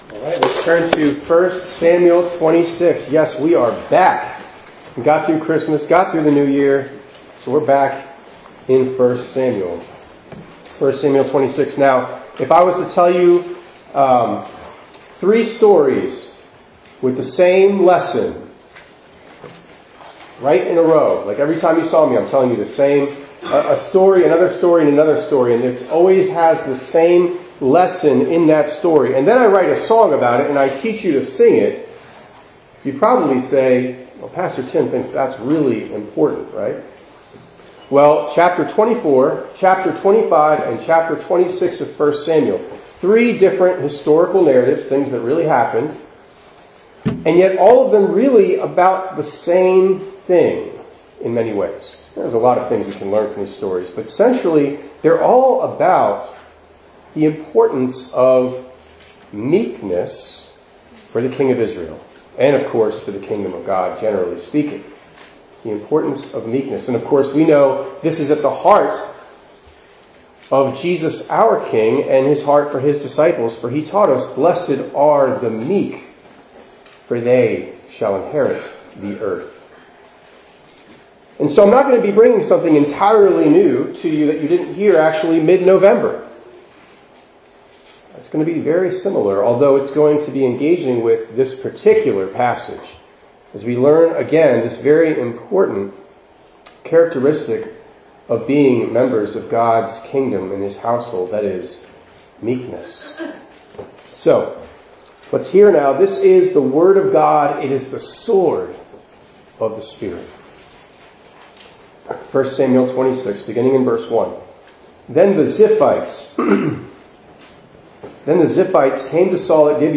1_18_26_ENG_Sermon.mp3